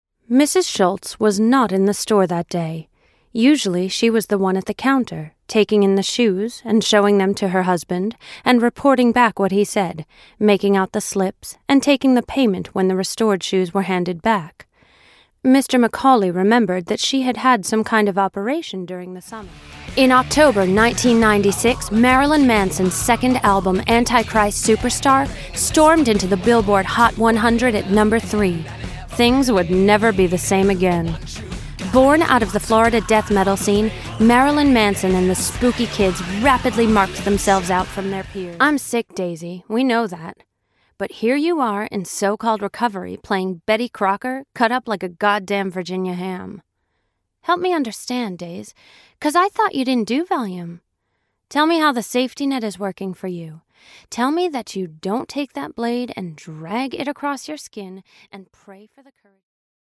Female, US, American, 20s, 30s, light, bright, commercial, advert, voiceover, voice over, DGV, Damn Good Voices, damngoodvoices, Crying Out Loud, cryingoutloud,